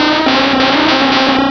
pokeemerald / sound / direct_sound_samples / cries / exeggutor.aif
-Replaced the Gen. 1 to 3 cries with BW2 rips.